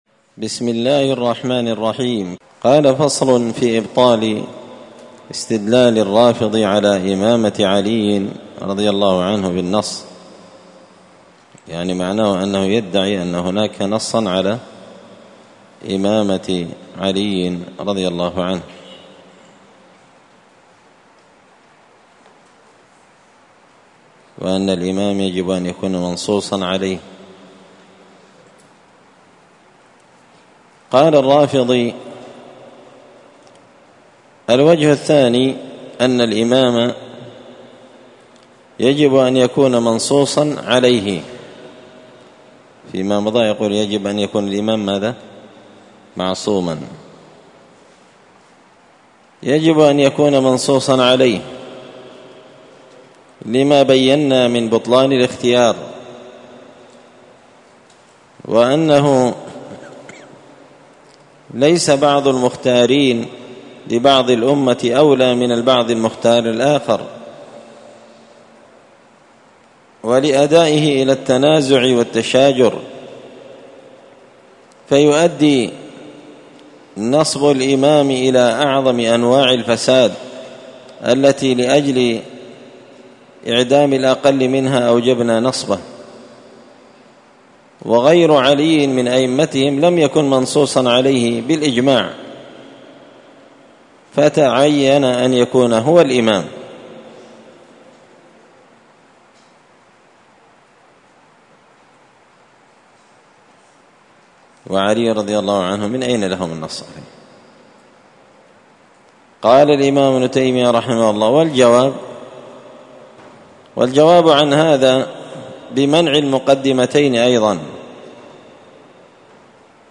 الأربعاء 15 محرم 1445 هــــ | الدروس، دروس الردود، مختصر منهاج السنة النبوية لشيخ الإسلام ابن تيمية | شارك بتعليقك | 9 المشاهدات
مسجد الفرقان قشن_المهرة_اليمن